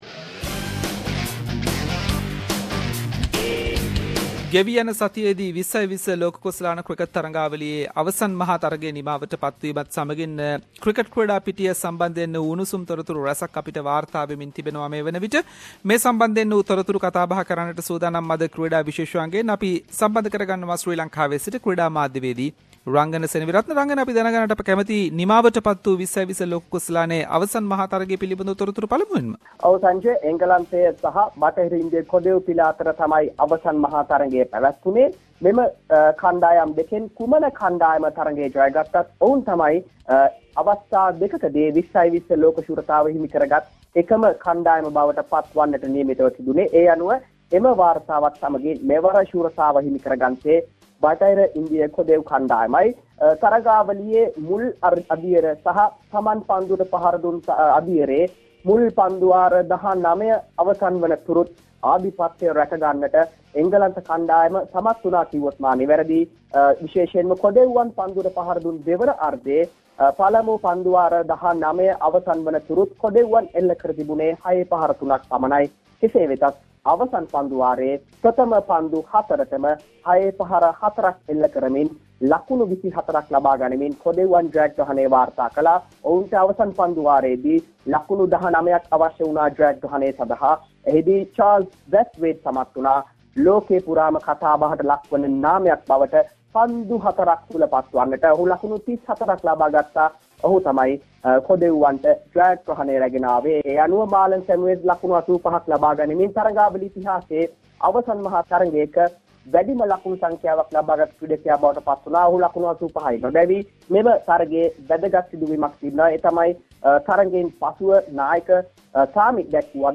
In this weeks SBS Sinhalese sports wrap…. Latest news realted 2016 T20 World cup, Lasith Malingas participation for 2016 IPL and many more sports news.